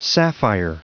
Prononciation du mot sapphire en anglais (fichier audio)
Prononciation du mot : sapphire